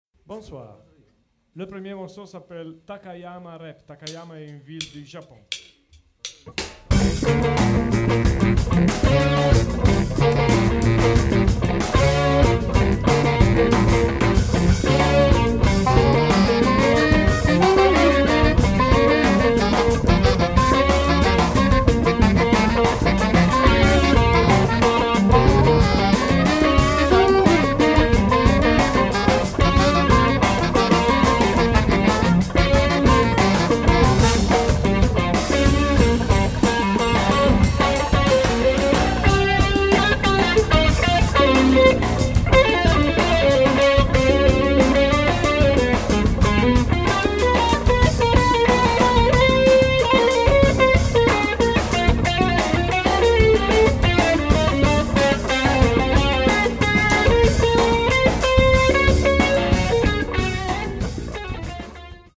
sassofono tenore e baritono, clarinetto basso
sassofono alto e soprano
chitarra
basso
batteria
registrato in Svizzera